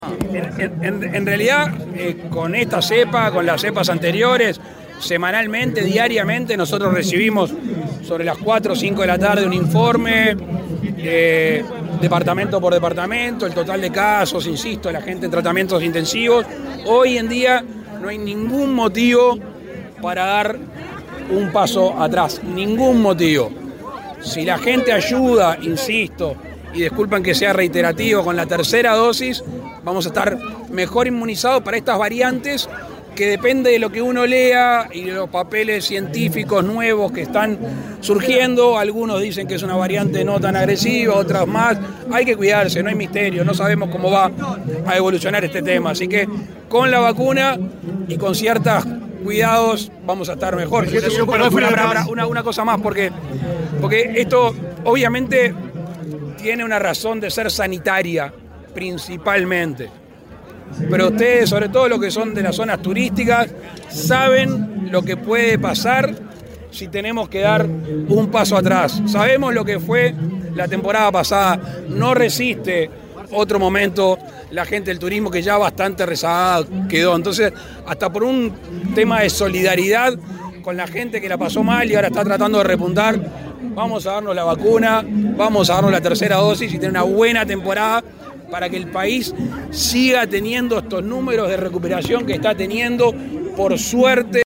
El presidente de la República Luis Lacalle Pou en rueda de prensa, se refirió a la nueva  variante del Coronavirus ómicron.